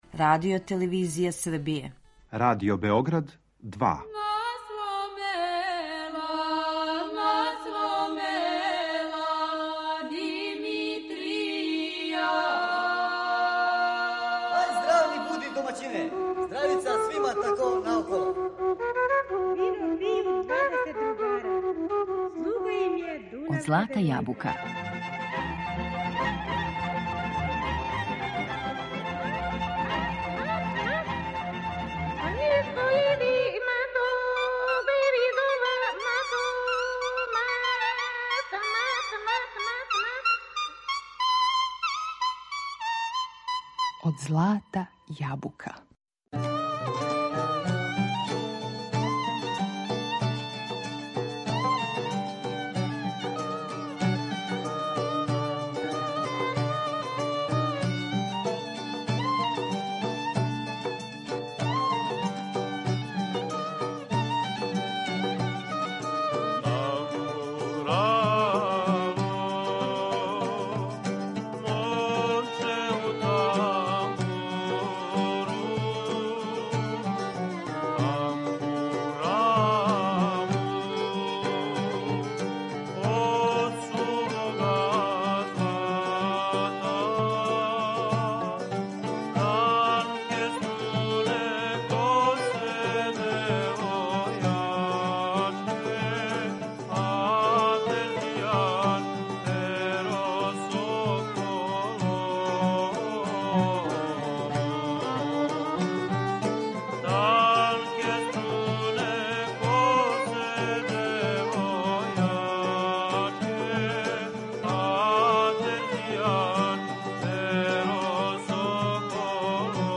Прескочићемо уобичајени увод који подразумева порекло, развој инструмента и најистакнутије композиторе и вође тамбурашких састава да бисмо се препустили слушању звука овог и сродних му инструмената, улози у различитим формацијама што у приватној, што у јавној сфери, а највише савременом музичком извођењу.